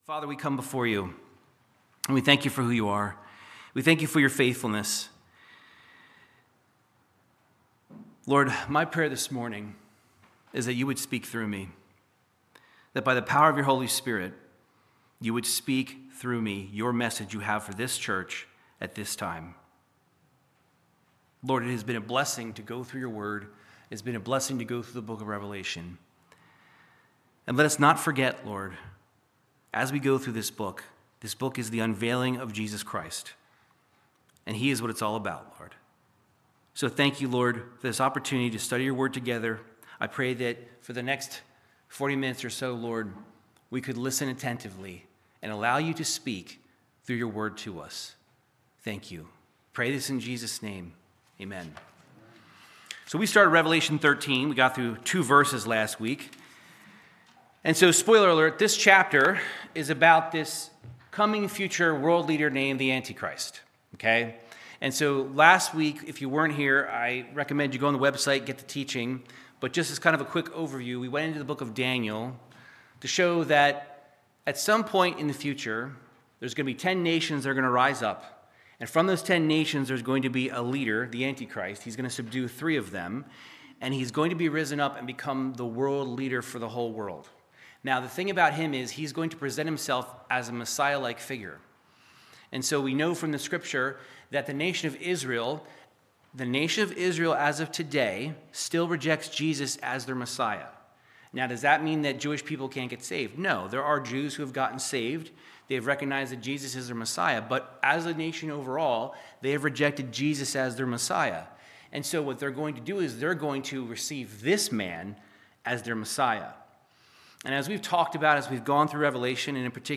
Verse by verse Bible teaching through the book of Revelation chapter 13